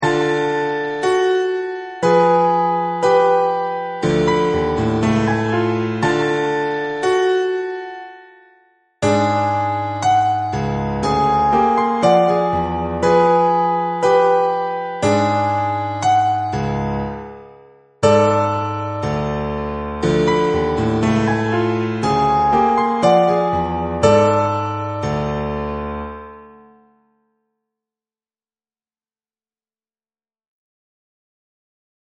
Les mesures répétées créent chaque fois une relative cohérence du morceau, mais c'est tout de même pour les petites tailles n que cela fonctionne le mieux.
Grannet de mesures à 3 voix no 4 (255 Ko)